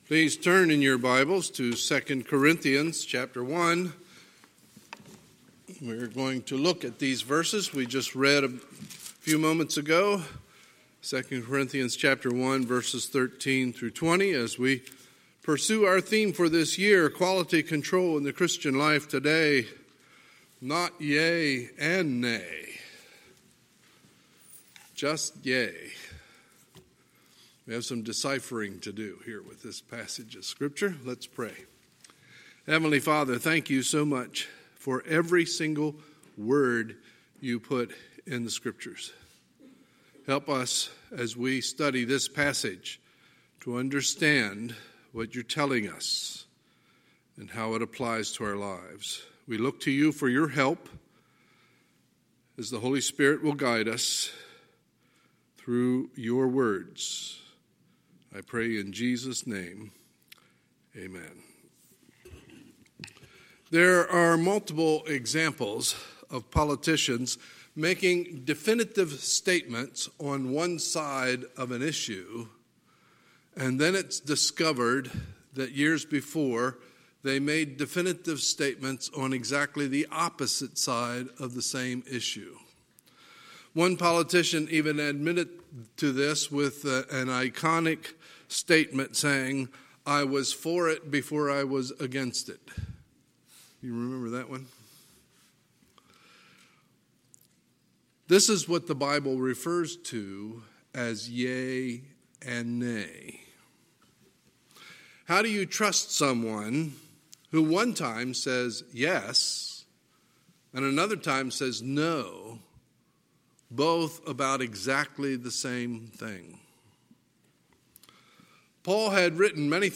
Sunday, January 19, 2020 – Sunday Morning Service